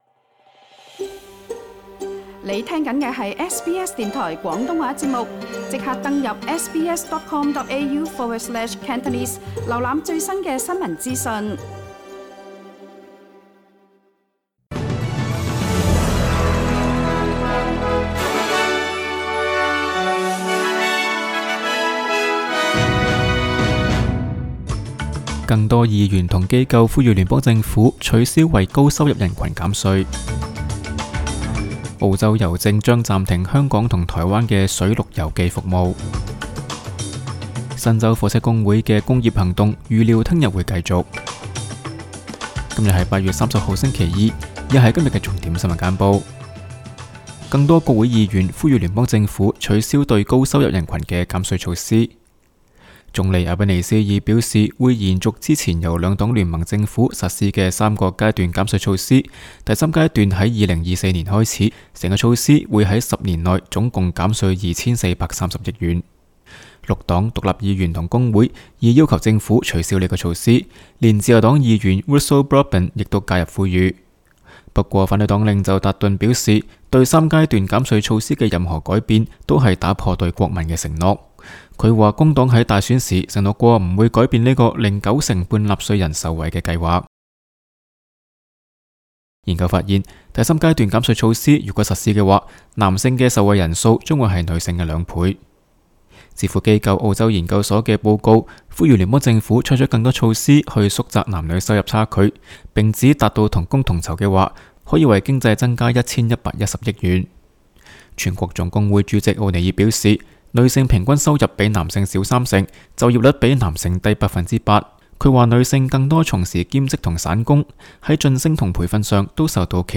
SBS 廣東話節目新聞簡報 Source: SBS / SBS Cantonese